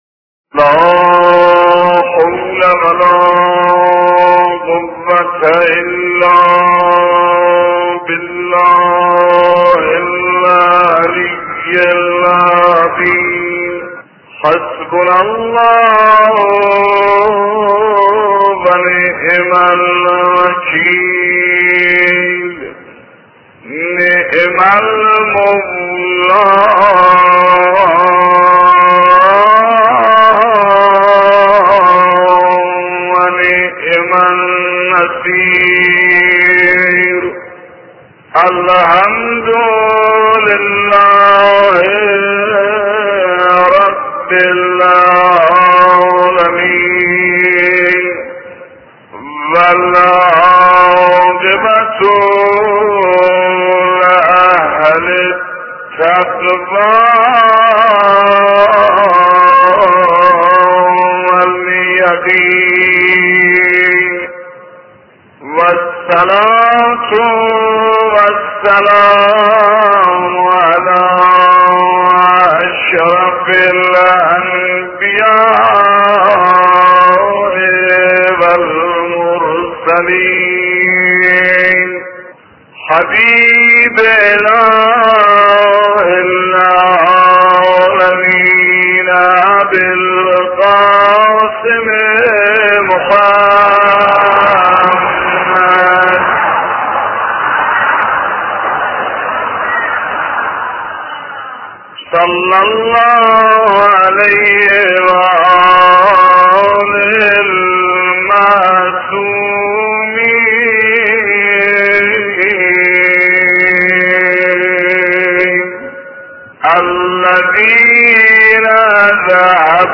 روضه درباره امام زمان (عج) و فرج ایشان 1